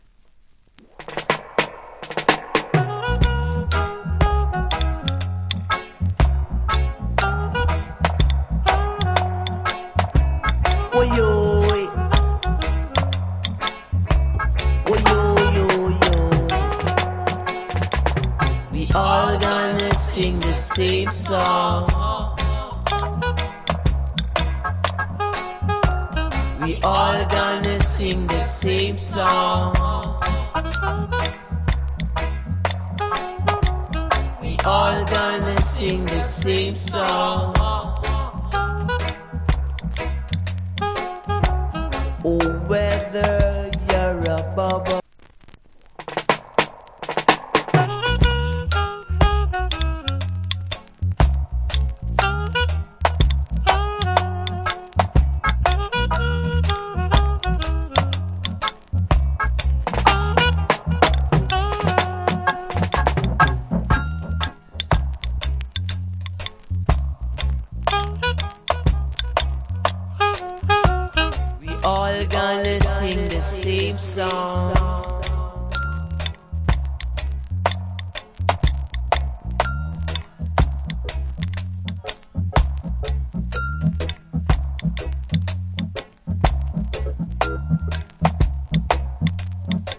Foundation Roots Rock Vocal.